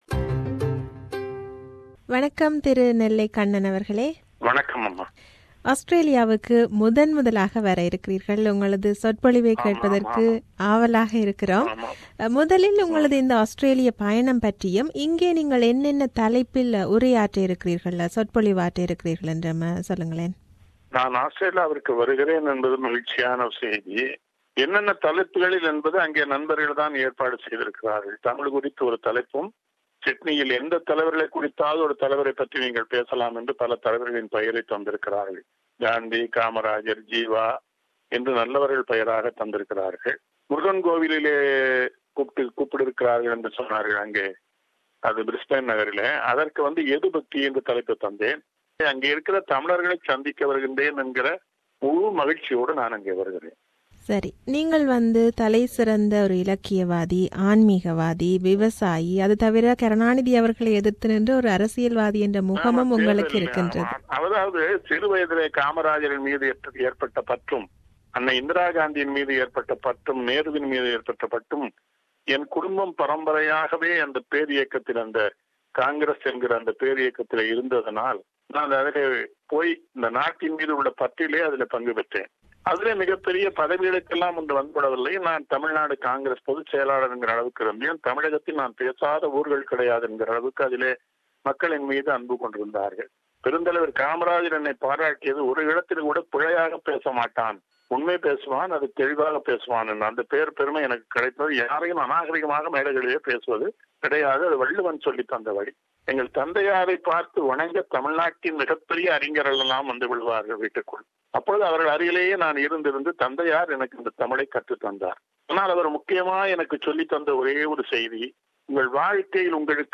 Tamil Arts and Culture Associations Iniya Ilakkiya Deepavali Santhippu will be held on 19 Nov 2016 at 5.30 at Sri Durgai Amman Temple,Regents Park, NSW 2144. This is an interview with Mr.Nellai Kannan.